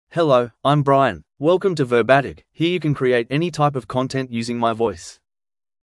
Brian — Male English (Australia) AI Voice | TTS, Voice Cloning & Video | Verbatik AI
MaleEnglish (Australia)
Voice sample
Male
English (Australia)
Brian delivers clear pronunciation with authentic Australia English intonation, making your content sound professionally produced.